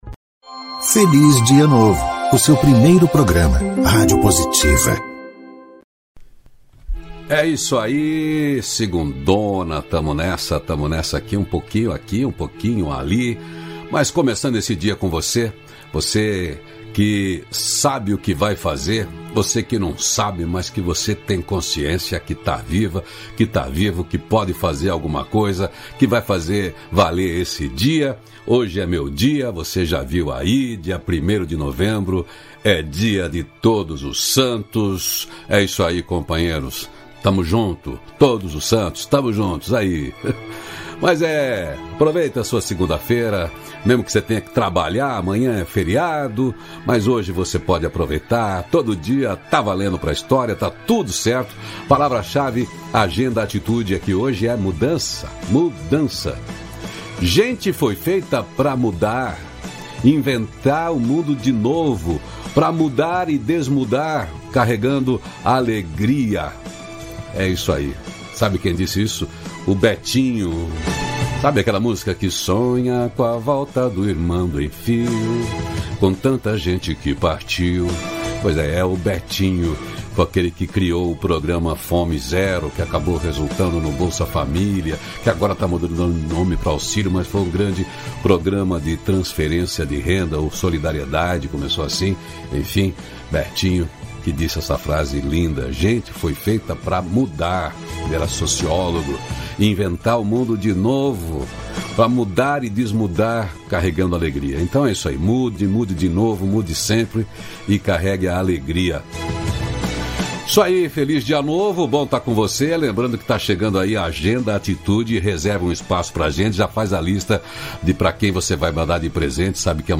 Diálogo nutritivo